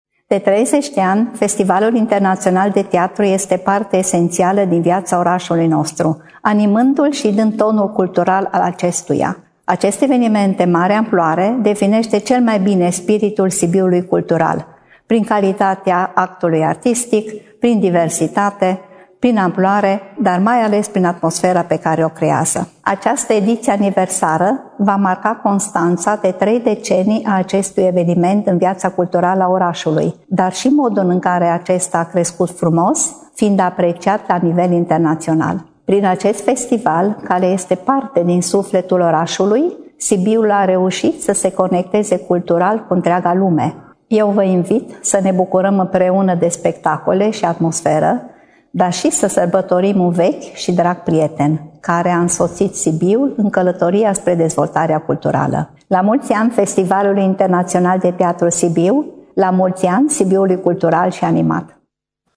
Primarul Sibiului, Astrid Fodor, a participat și s-a arătat mândră de FITS:
insert-Astrid-Fodor-FITS.mp3